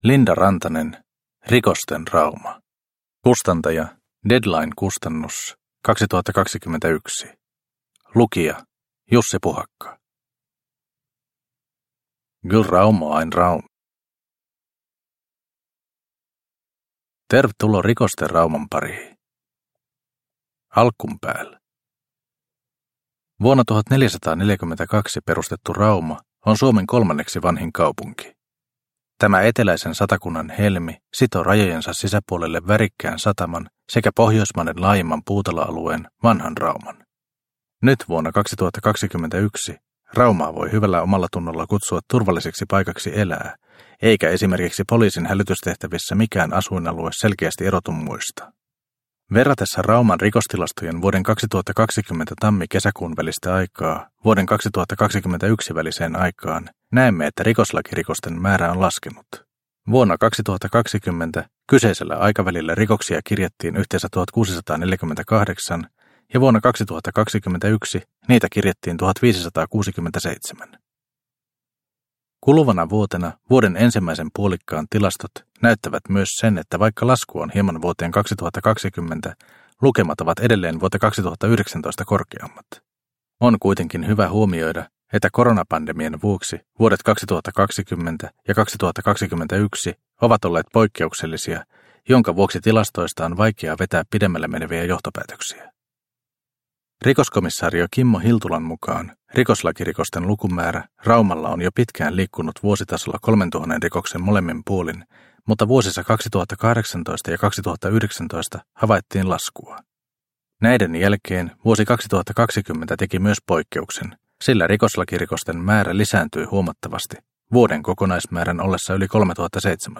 Rikosten Rauma – Ljudbok – Laddas ner